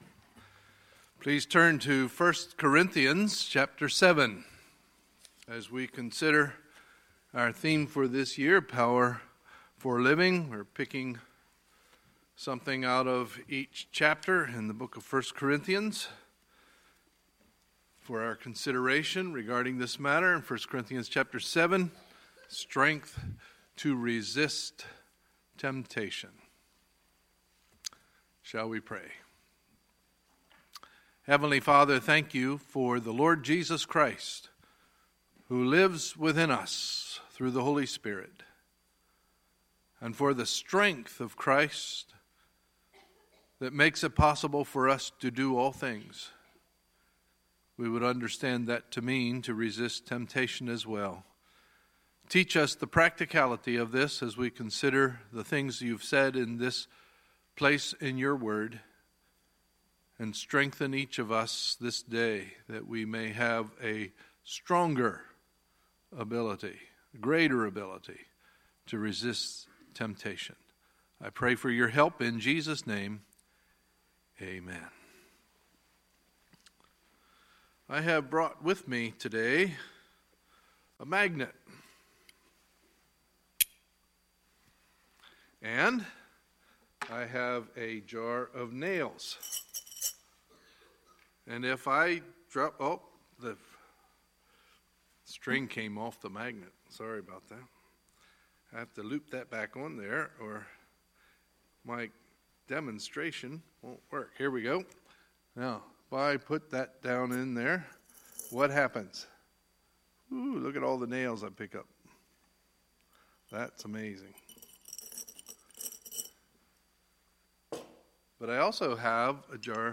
Sunday, March 12, 2017 – Sunday Morning Service